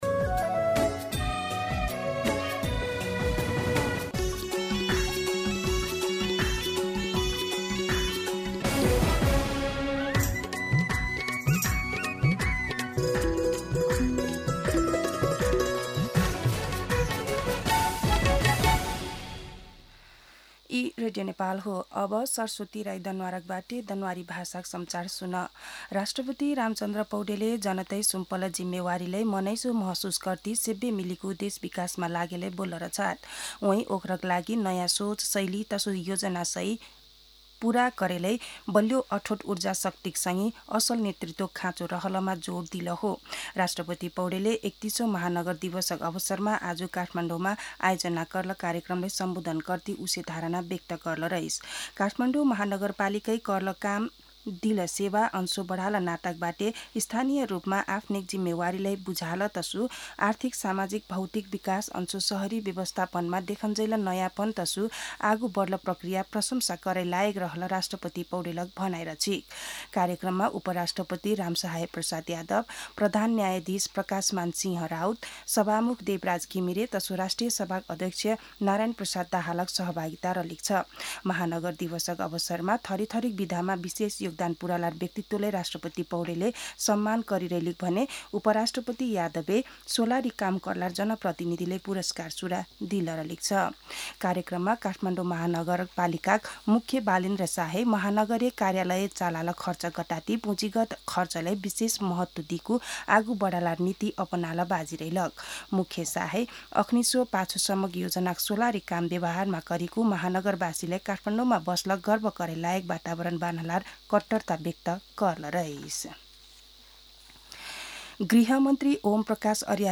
दनुवार भाषामा समाचार : २९ मंसिर , २०८२
Danuwar-News-8-29.mp3